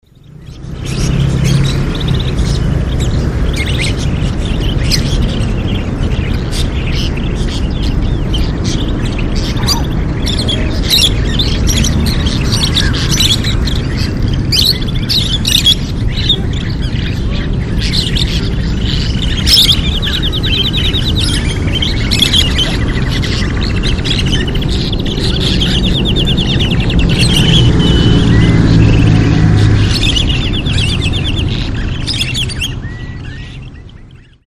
Taipei Bird Market Location Recordings
While exploring the area between the Confucius Temple and the Baoan Temple, I came across a man and his group of about 2 dozen bird cages. The cages contained many striking examples of various species of exotic birds. I placed my binaural microphones amidst the cages and captured the sounds of the birds and the wide variety of songs they sing.